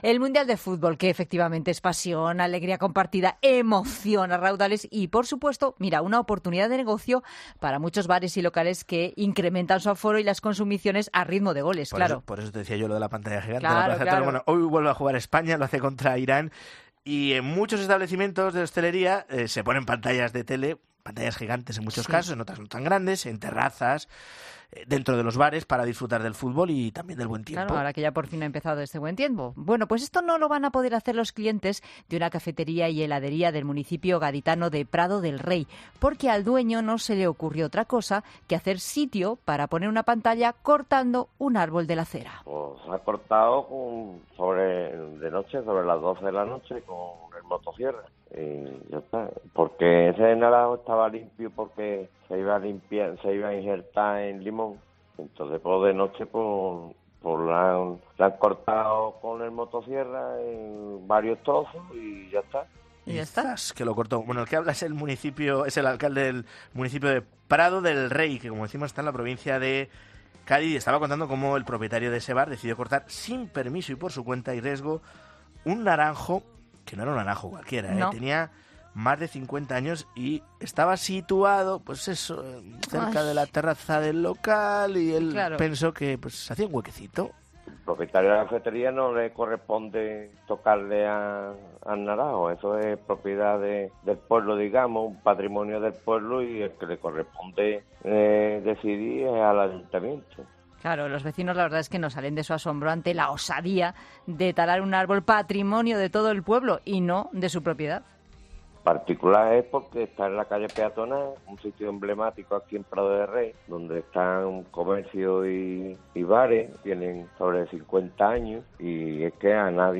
“Ha cortado el naranjo en torno a las 12 de la noche con una motosierra”, ha explicado en 'Mediodía COPE' el alcalde del municipio.